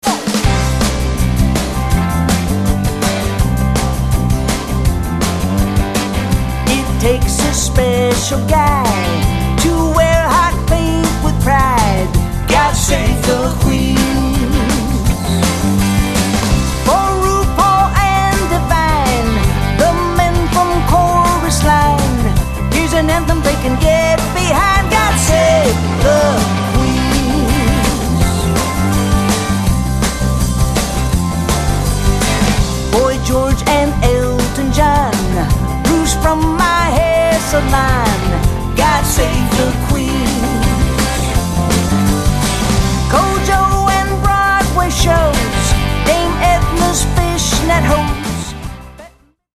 comedy music